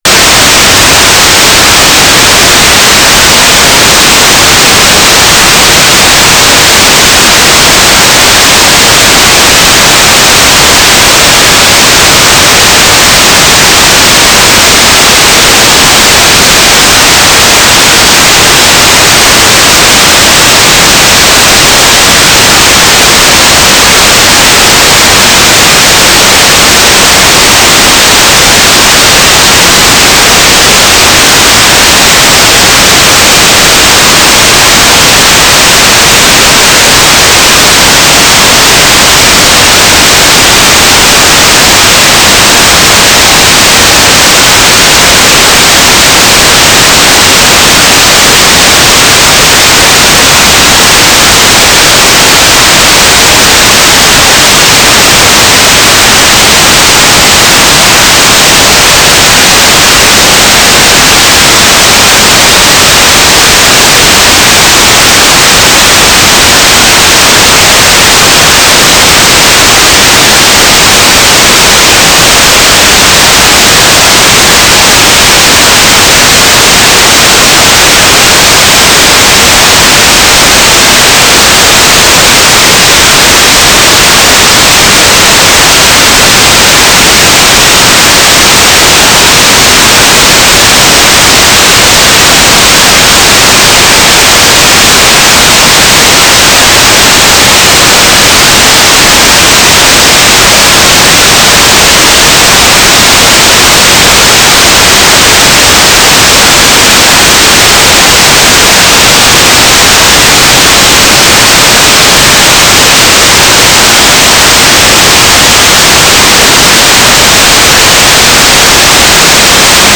"transmitter_description": "Downlink",
"transmitter_mode": "FSK",